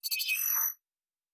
pgs/Assets/Audio/Sci-Fi Sounds/Weapons/Additional Weapon Sounds 2_5.wav at master
Additional Weapon Sounds 2_5.wav